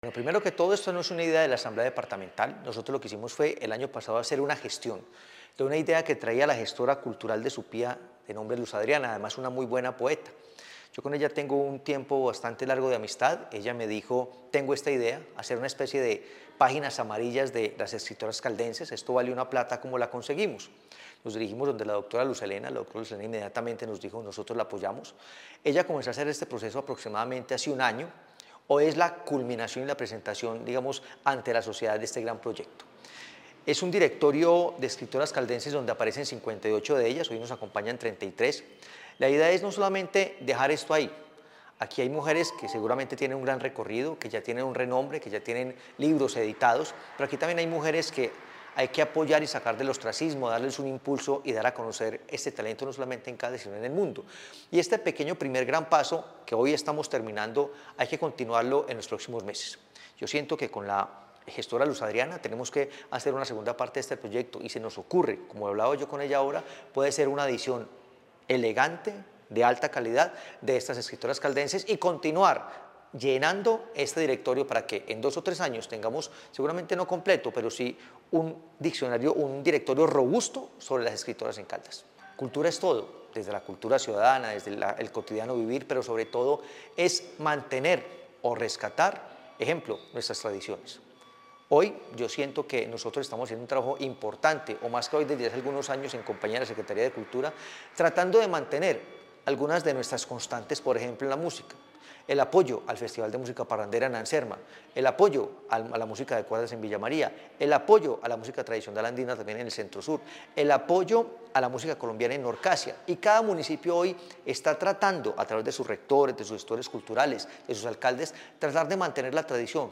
Hernán Alberto Bedoya, presidente de la Asamblea de Caldas